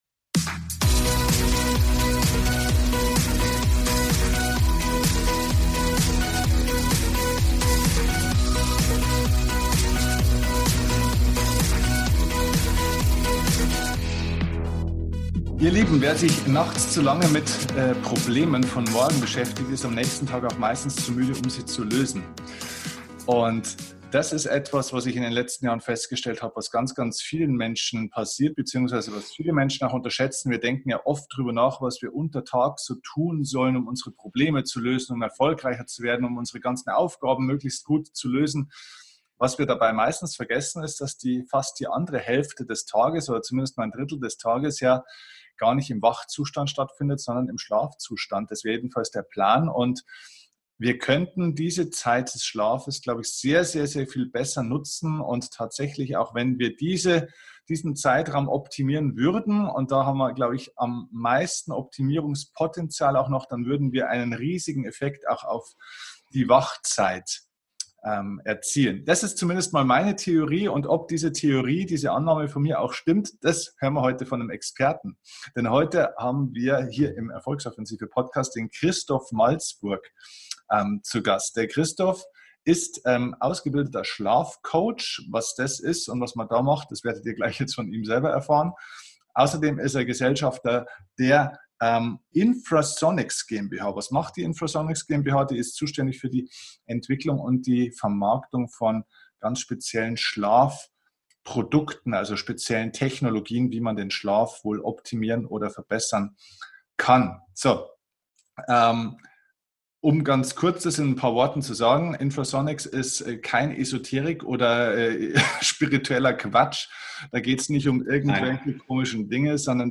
#302 Bessere Leistung durch besseren Schlaf - Interview